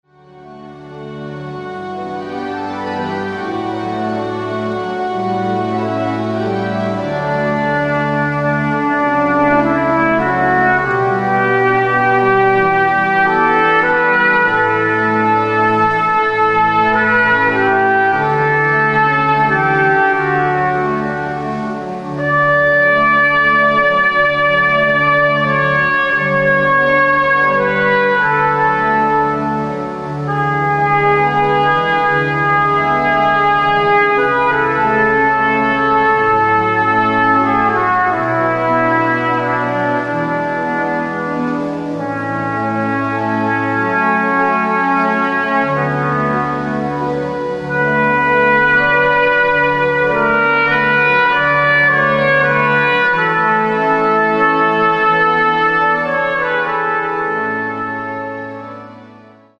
prelude music (mp3) joyful but prayerful by using baroque and classical selections.
Listen to audio of Arcadia Brass playing some of our most frequently requested wedding music...